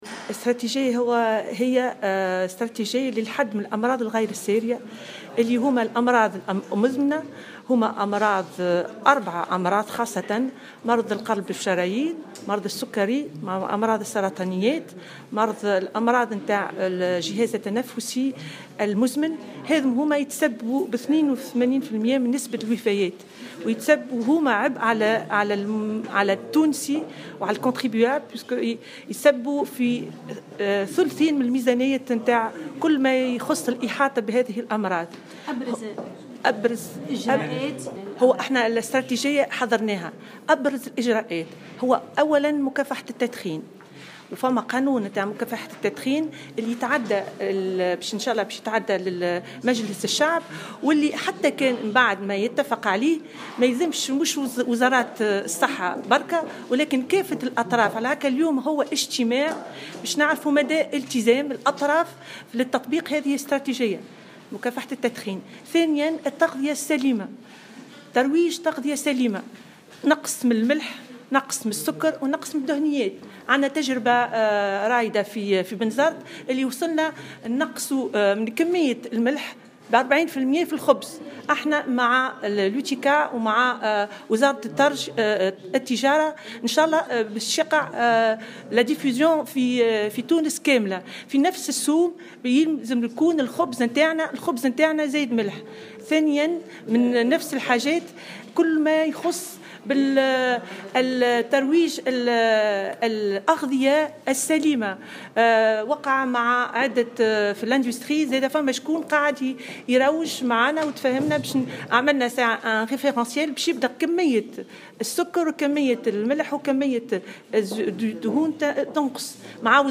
أكدت الرئيسة المديرة العامة للديوان الوطني للأسرة والعمران البشري" رافلة تاج" في تصريح لمراسل الجوهرة "اف ام" على هامش ندوة لتقديم الاستراتيجية الوطنية للحد من الأمراض غير السارية اليوم الإثنين إن وزارة الصحة انطلقت في هذه الاستراتيجية بالتنسيق مع عدة وزارات من خلال جملة من الإجراءات.